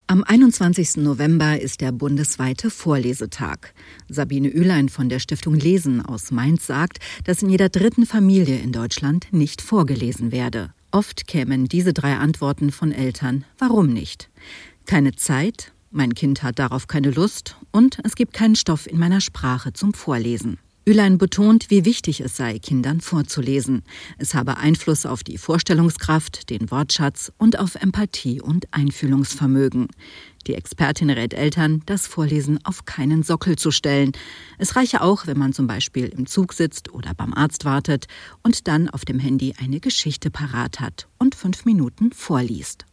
Interview mit Leseexpertin: "Vorlesen ist eine Art Superkraft"